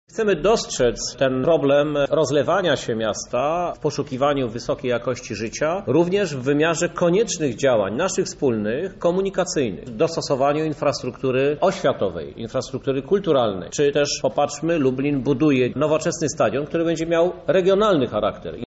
Mamy już jasno określone cele w strategii Lublin 2014-2020 – dodaje prezydent miasta Krzysztof Żuk.